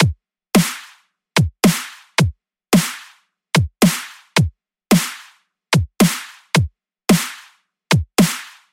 踢腿小号循环，Dubstep Drum And Bass
描述：只需循环踢腿和小鼓，用于dubstep或drumbass（没有percs hat ride等）。
Tag: 110 bpm Dubstep Loops Drum Loops 1.47 MB wav Key : Unknown